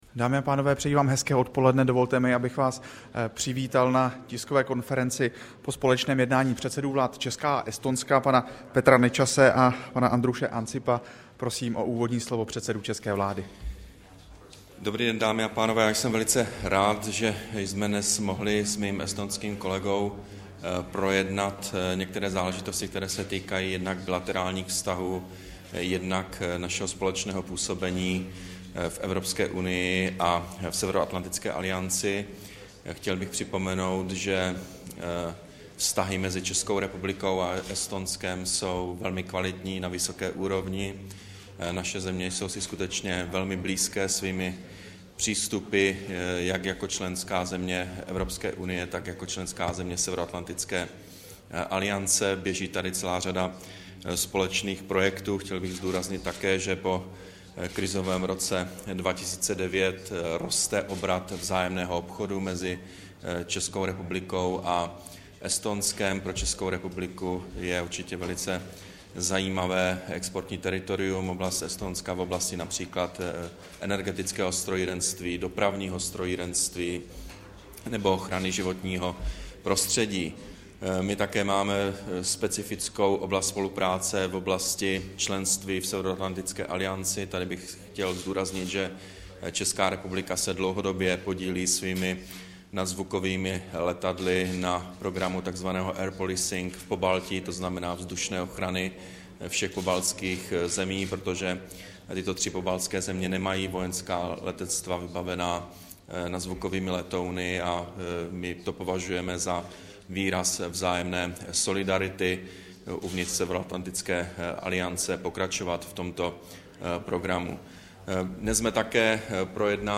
Tiskový brífink po jednání premiéra Petra Nečase s předsedou vlády Estonska Andrusem Ansipem, 22. listopadu 2011